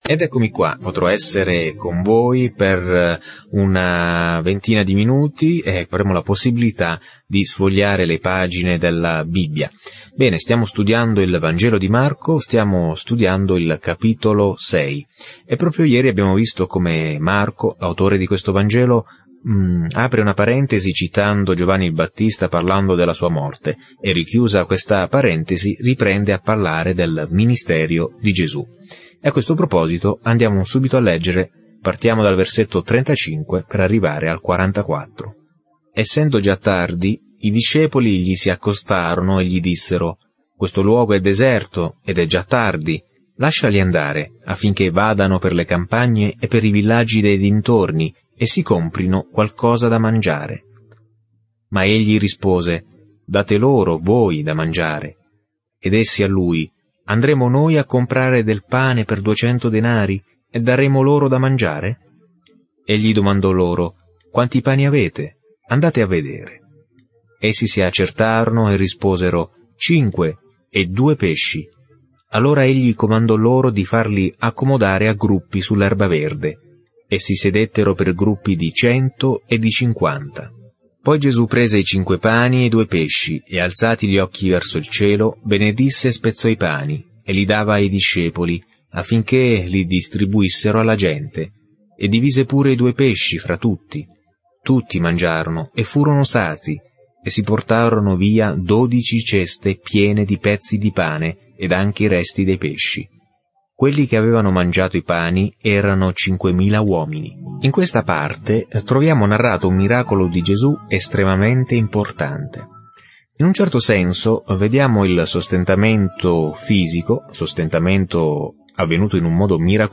Scrittura Vangelo secondo Marco 6:35-56 Vangelo secondo Marco 7:1-5 Giorno 8 Inizia questo Piano Giorno 10 Riguardo questo Piano Il Vangelo più breve di Marco descrive il ministero terreno di Gesù Cristo come Servo sofferente e Figlio dell’uomo. Viaggia ogni giorno attraverso Marco mentre ascolti lo studio audio e leggi versetti selezionati della parola di Dio.